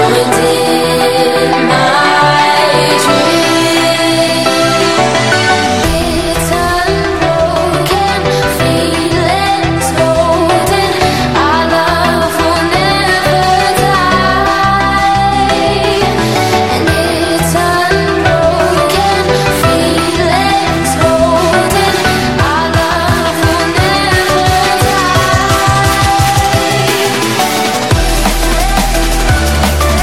TOP >Vinyl >Drum & Bass / Jungle